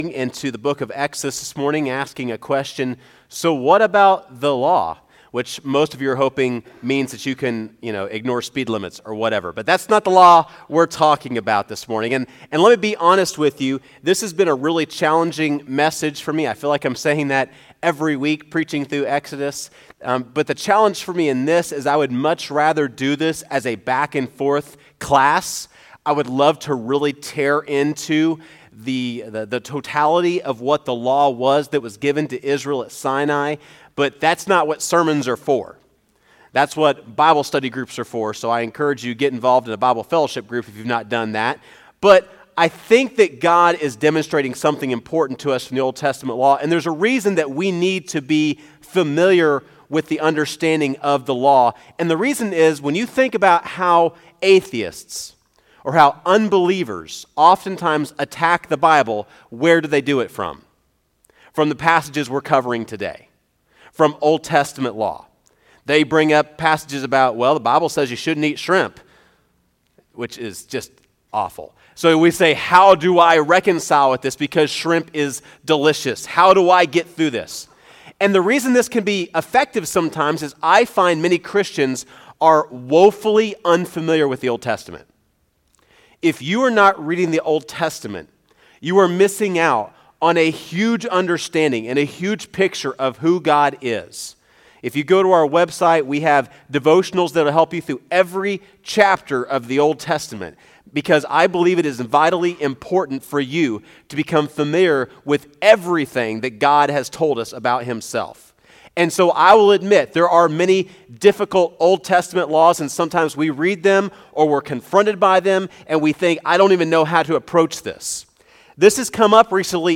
First Baptist Church of Machesney Park Sermon Audio